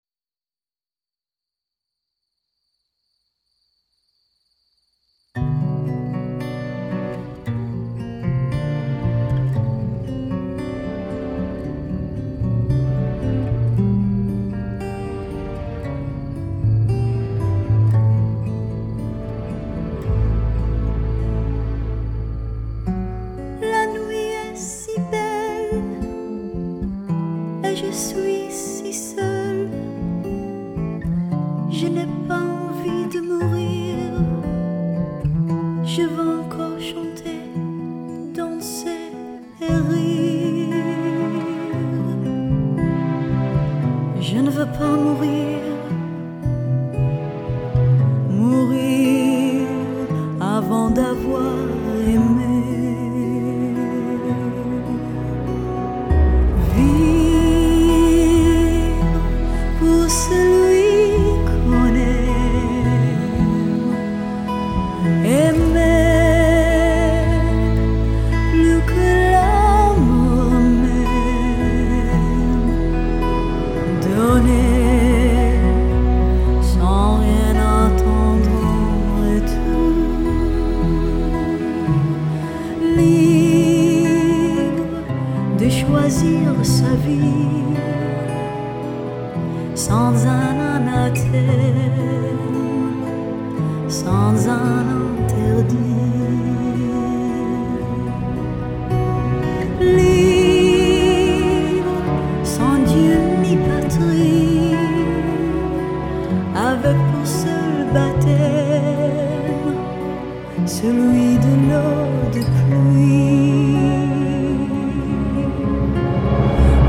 альбом - мюзикл, франц. версия